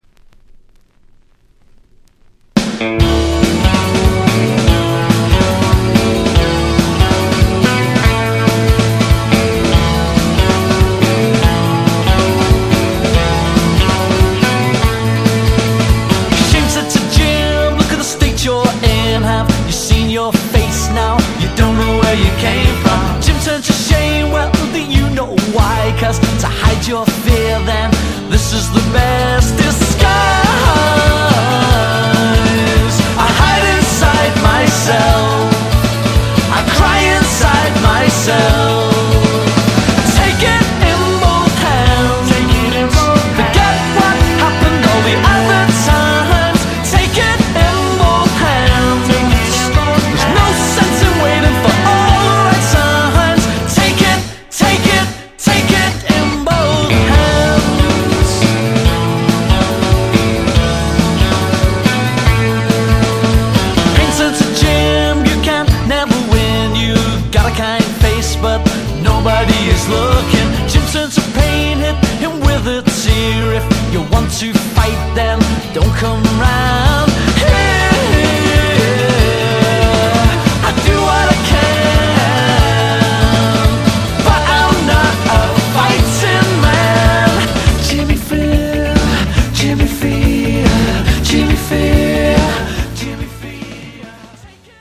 80'S - 90'S RARE NEO ACOUSTIC LISTS  (A-D)